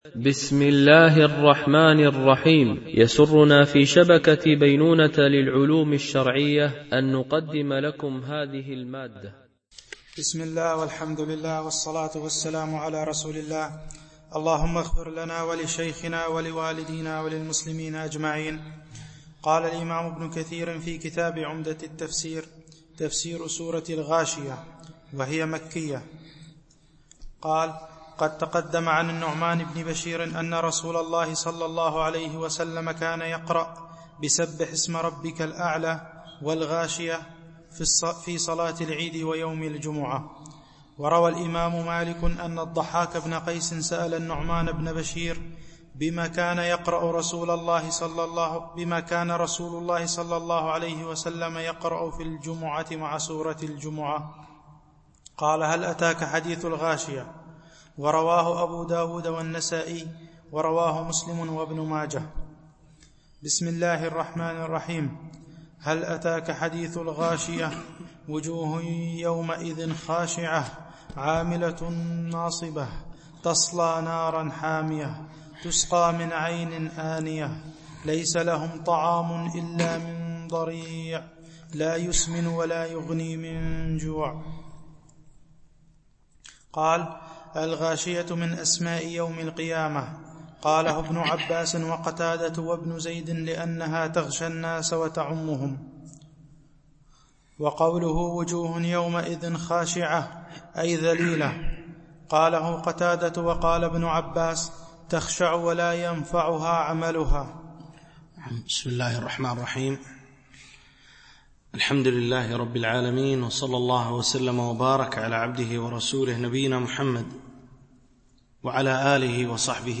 شرح مختصر تفسير ابن كثير(عمدة التفسير) الدرس 53 (سورة الغاشية والفجر)
MP3 Mono 22kHz 32Kbps (CBR)